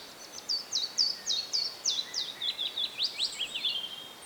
Example twelve. Many song-phrases beginning with Chiffchaff notes then merging seamlessly into Willow Warbler notes.
A single 'switched' phrase can be heard <